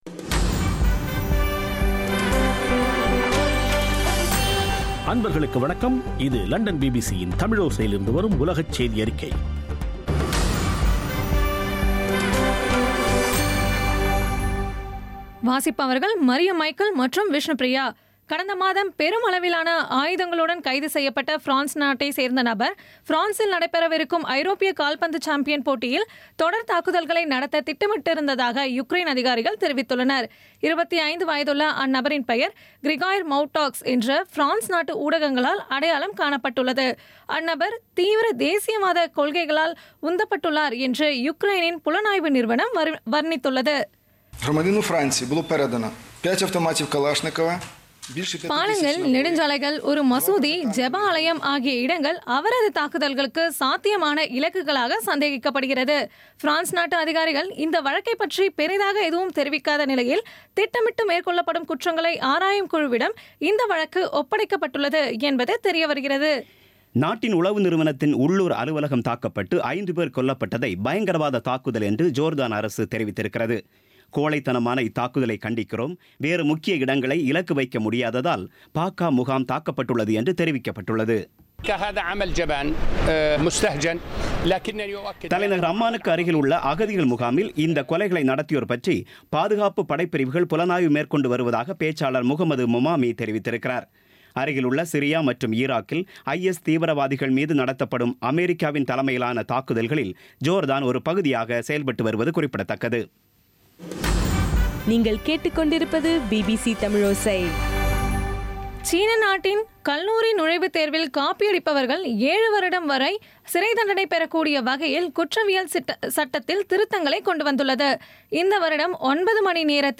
இன்றைய (ஜூன் 6-ஆம் தேதி ) பிபிசி தமிழோசை செய்தியறிக்கை